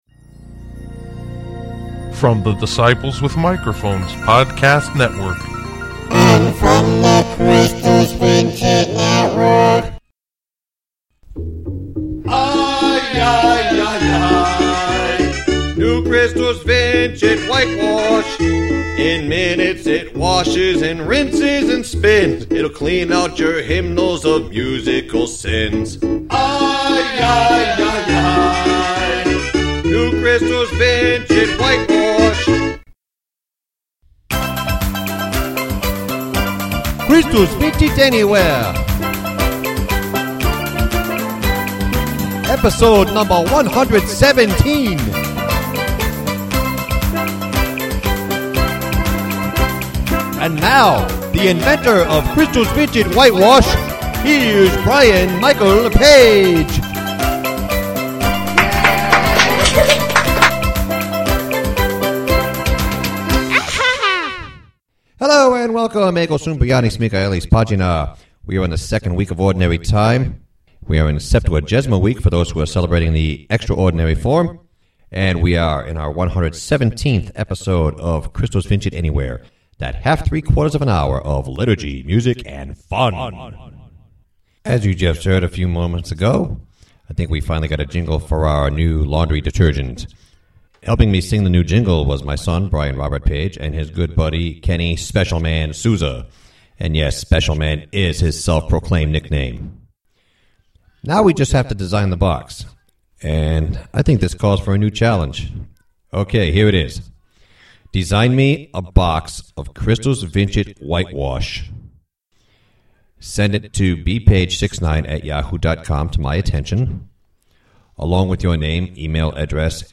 Christus Vincit Semi-Live on I-95: showcasing music for the Second Sunday of Ordinary Time (Mass in Ordinary Form at Blessed Sacrament ) and Septuagesima Sunday (Mass in Extraordinary Form at St. Leo the Great ).